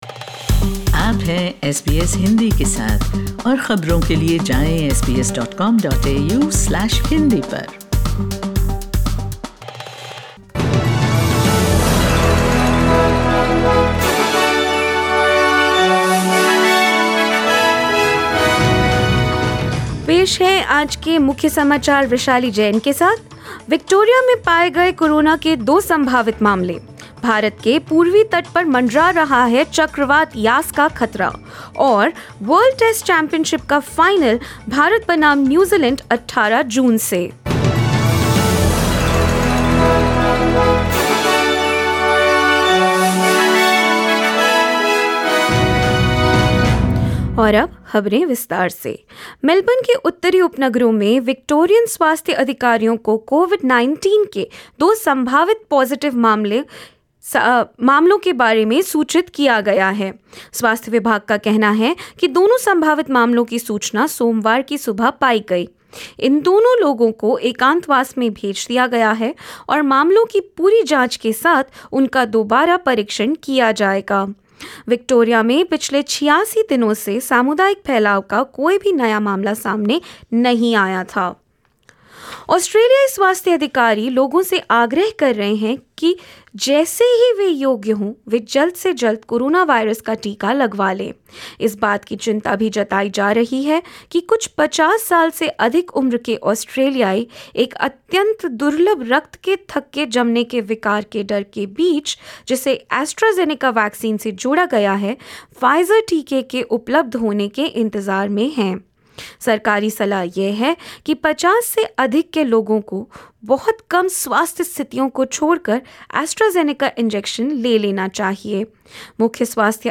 In this latest SBS Hindi News bulletin of India and Australia: Victoria reports new Covid-19 cases; cyclone Yaas to make landfall on India's eastern coast in two days and more.